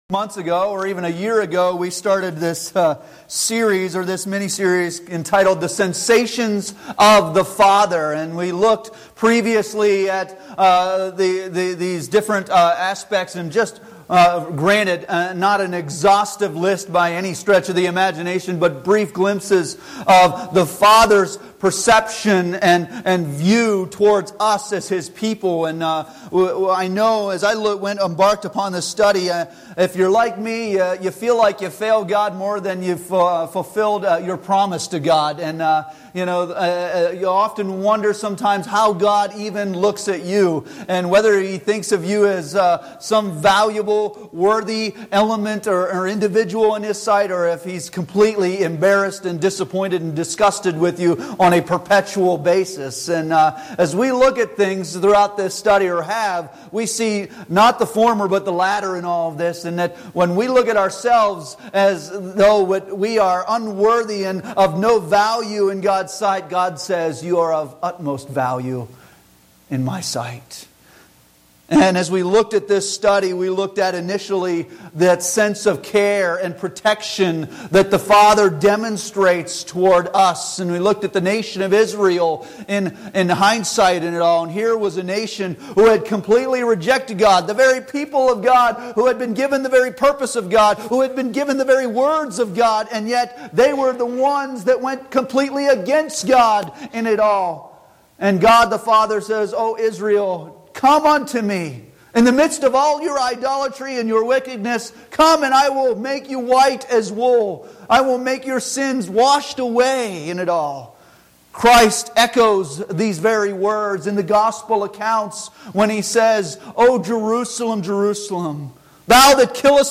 8:33-39 Service Type: Sunday Morning Worship Bible Text